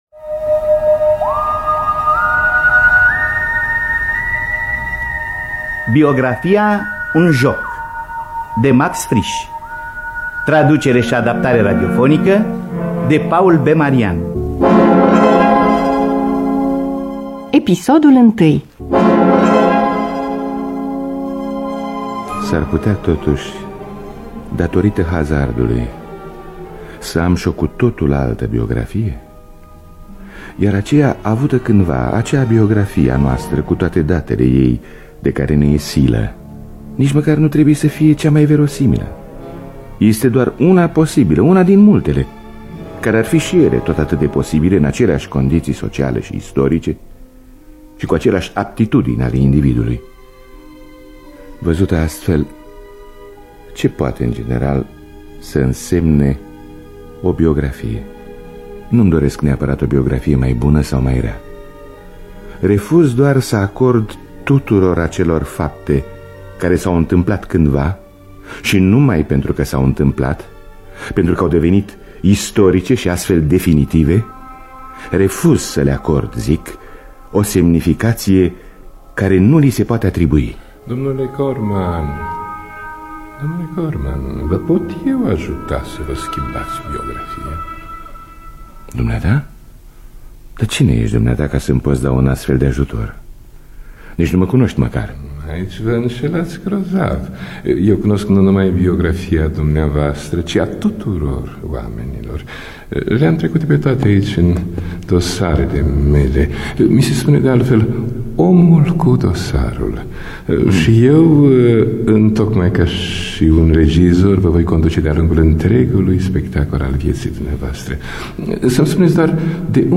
Traducerea şi adaptarea radiofonică